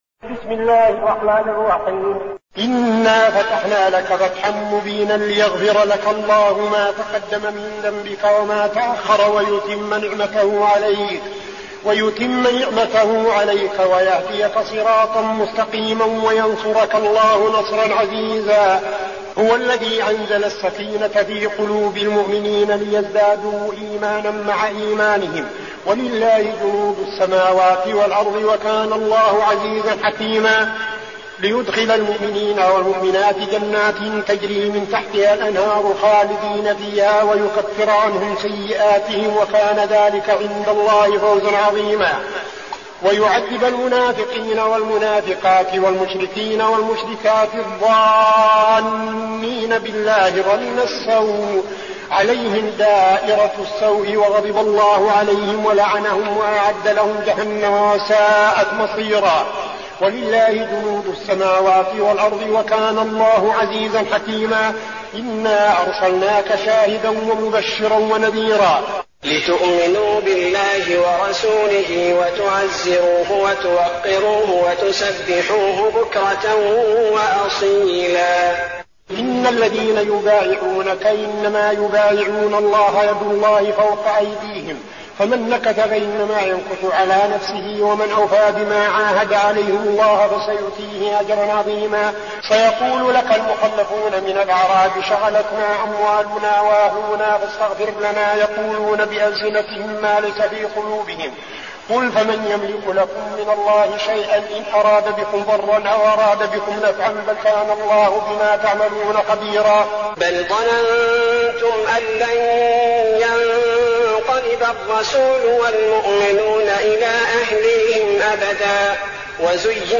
المكان: المسجد النبوي الشيخ: فضيلة الشيخ عبدالعزيز بن صالح فضيلة الشيخ عبدالعزيز بن صالح الفتح